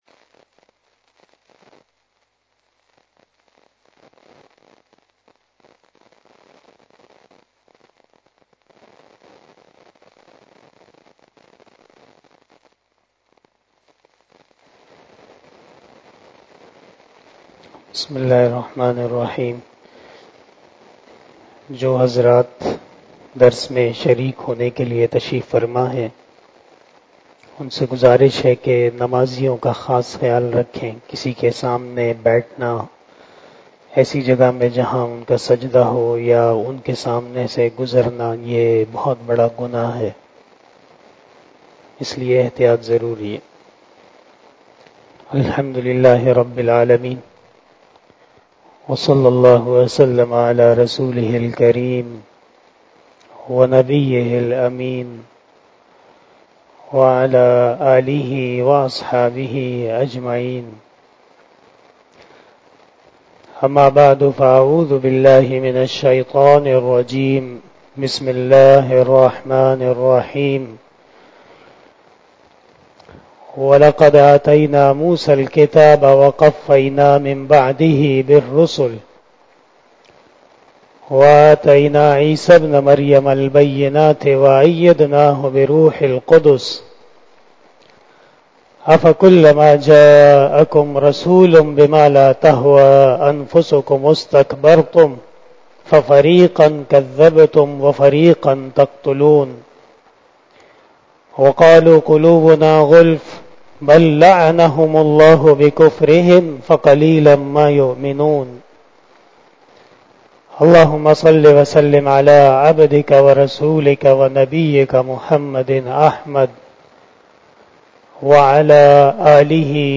Shab-e-Jummah Bayan